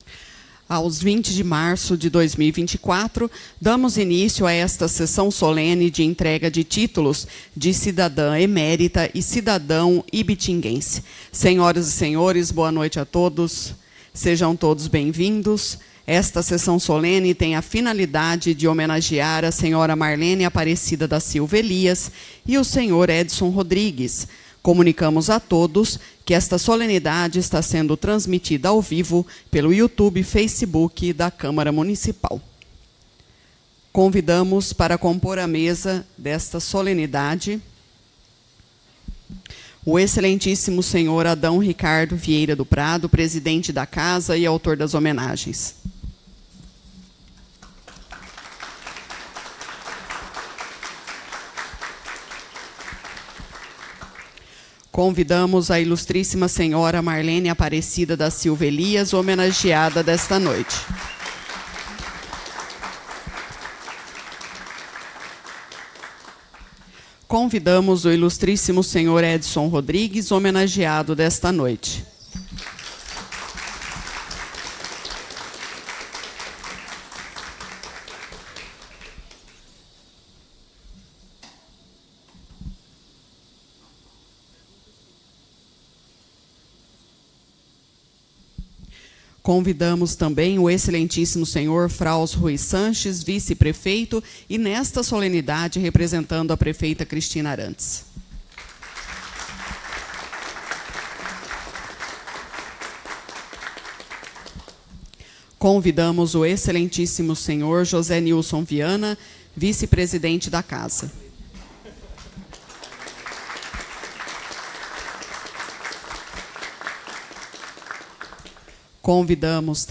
Sessões Solenes/Especiais